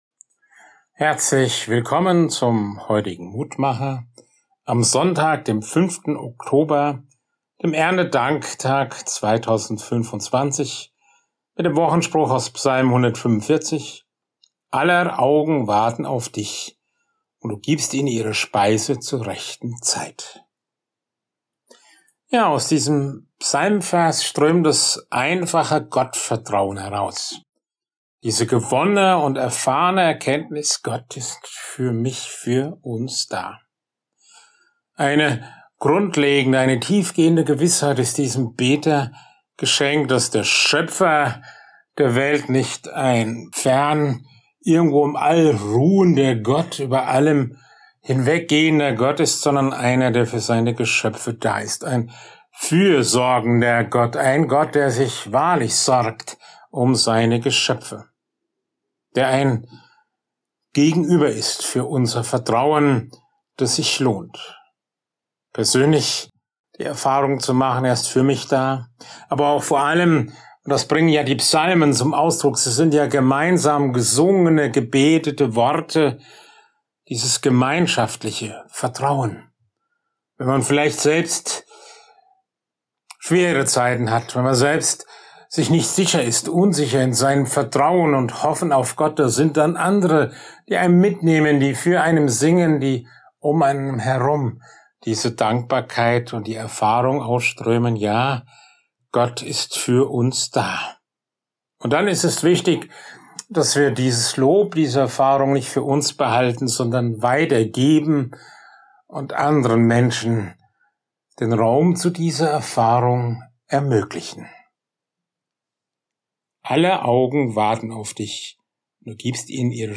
Mutmacher - Kleine Andacht zum Tag